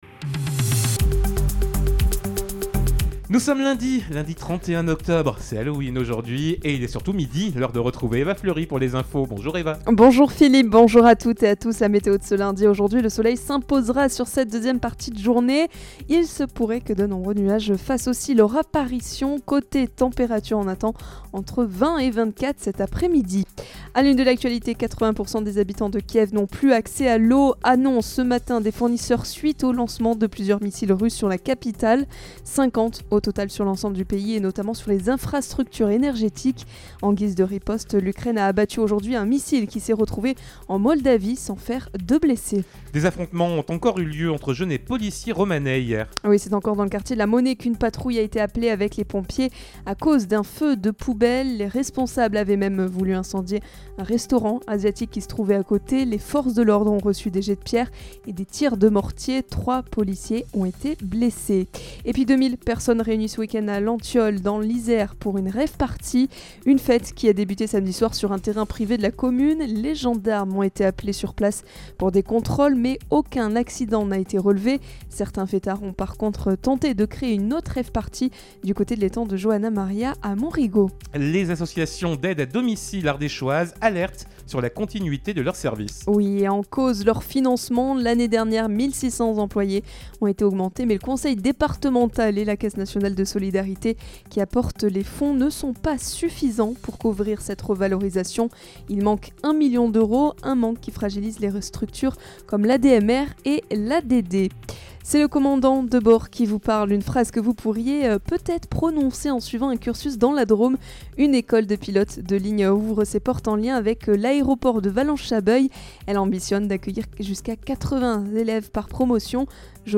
Lundi 31 octobre : Le journal de 12h